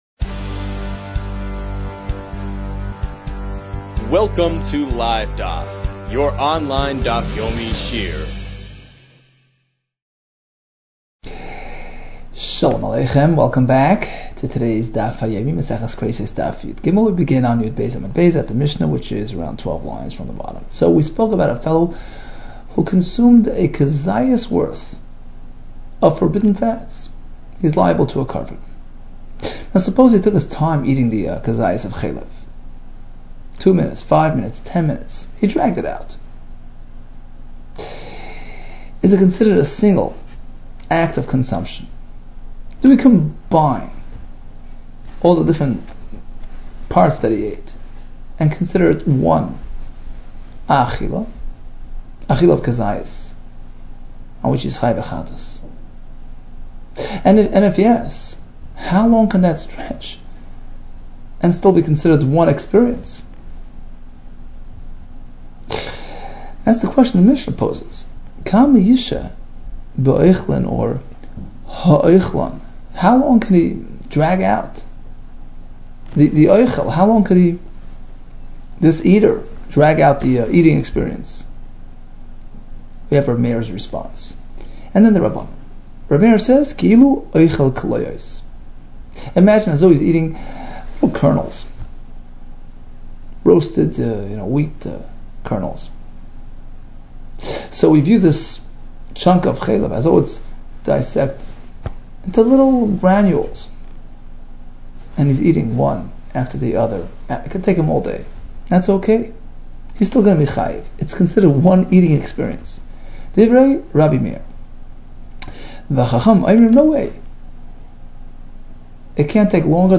Kereisos 13 - כריתות יג | Daf Yomi Online Shiur | Livedaf